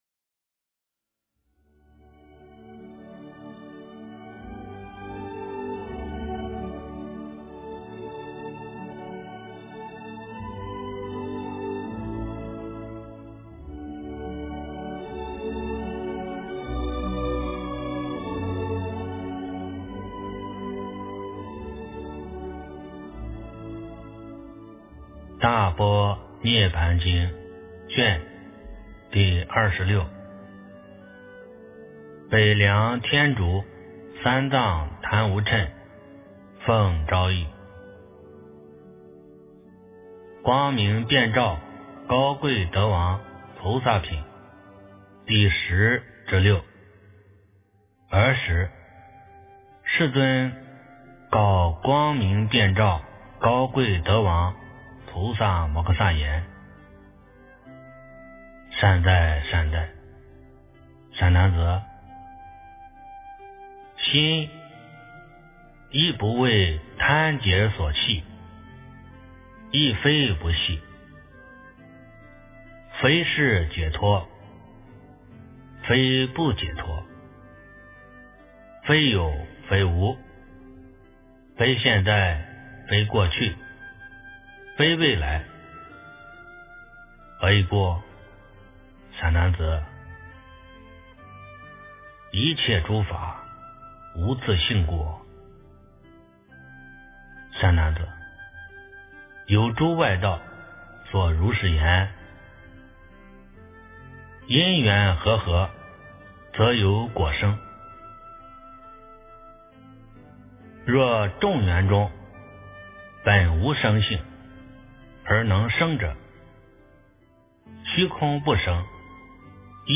诵经
佛音 诵经 佛教音乐 返回列表 上一篇： 大般涅槃经23 下一篇： 佛说四十二章经 相关文章 南无大智文殊师利菩萨--无名氏 南无大智文殊师利菩萨--无名氏...